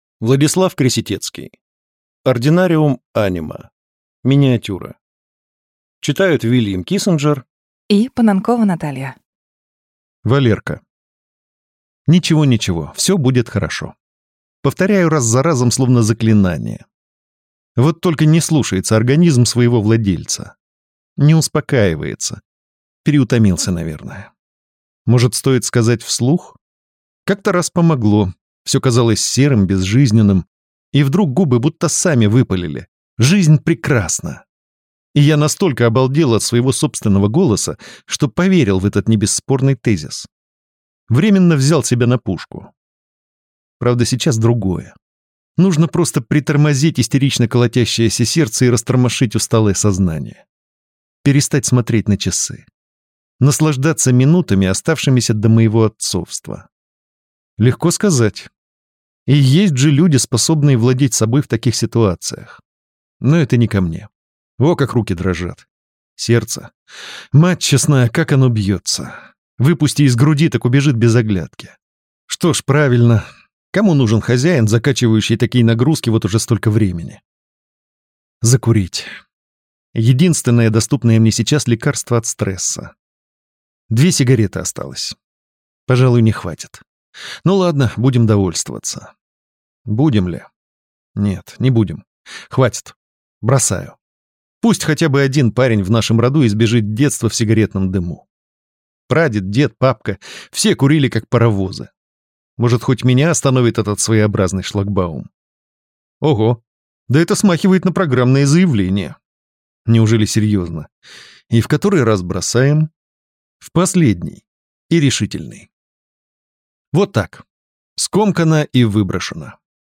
Аудиокнига Ординариум Анима | Библиотека аудиокниг